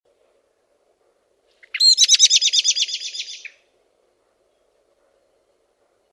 Mustarastaan räjähtävä varoituskiljaisu
Kun mustarastas hermostuu ja pakenee, sen ääni purkautuu poukkoilevaksi ja räjähtäväksi varoituskiljaisuksi.